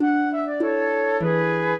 flute-harp
minuet6-2.wav